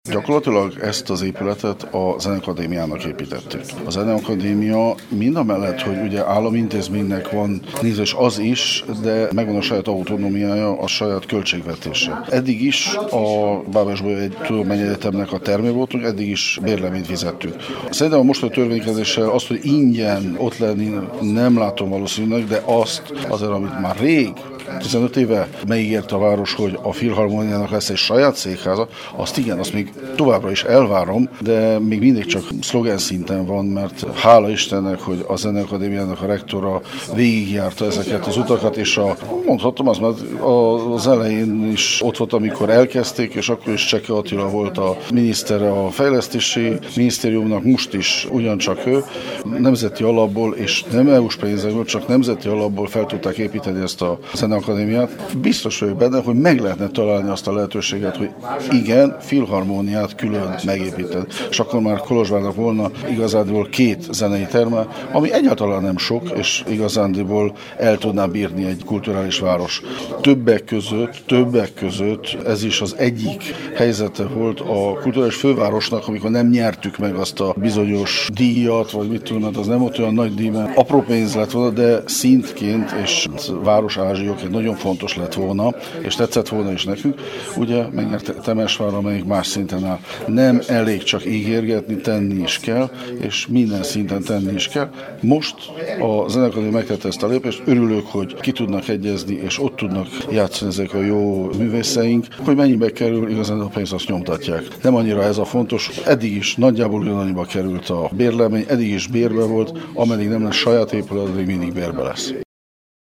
A Filharmónia most mégis bérleményből bérleménybe költözött át. Miért nem lakhat ingyenesen a Kolozs Megyei Tanács által közpénzből fenntartott Filharmónia a  közpénzből felépített Zeneakadémián? A Kolozs Megyei Tanács alelnökét, Vákár Istvánt kérdezte